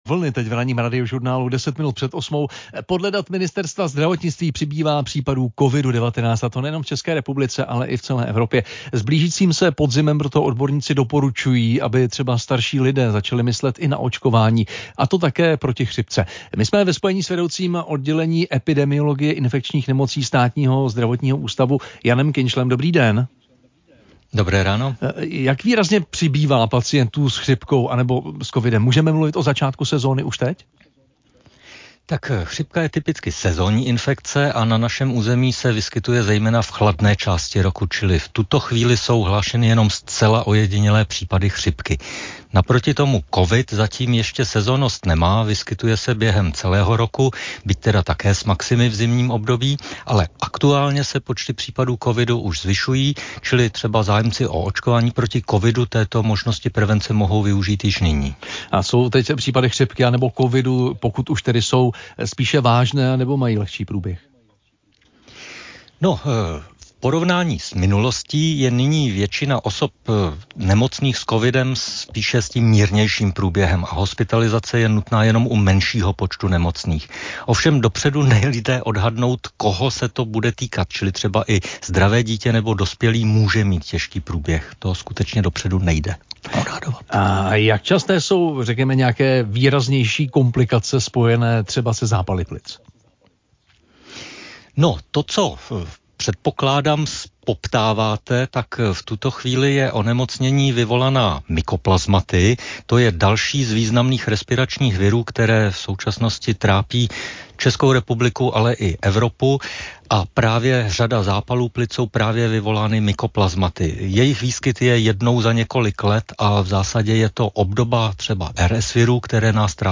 ČRo Radiožurnál: Podle dat ministerstva zdravotnictví přibývá případů covidu-19, a to nejenom v ČR, ale i v celé Evropě. S blížícím se podzimem proto odborníci doporučují, aby třeba starší lidé začali myslet i na očkování, a to také proti chřipce. V Ranním interview